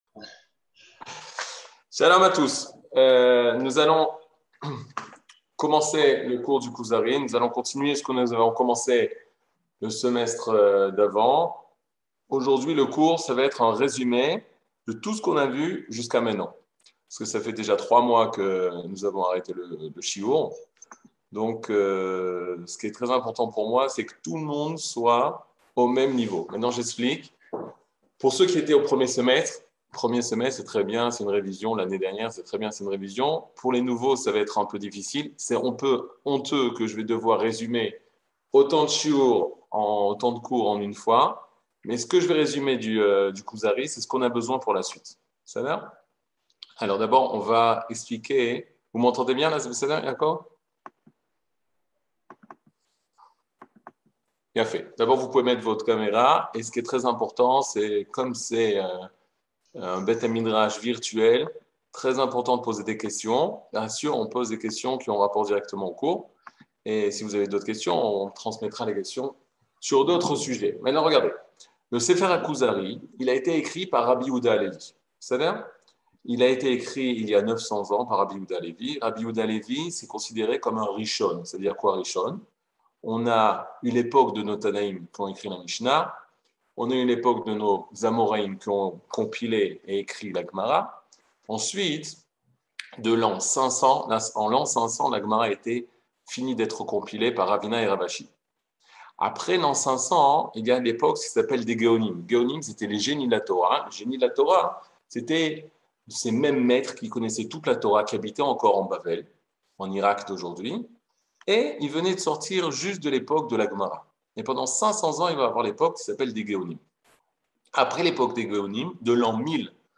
Catégorie Le livre du Kuzari partie 25 00:48:15 Le livre du Kuzari partie 25 cours du 16 mai 2022 48MIN Télécharger AUDIO MP3 (44.16 Mo) Télécharger VIDEO MP4 (123.35 Mo) TAGS : Mini-cours Voir aussi ?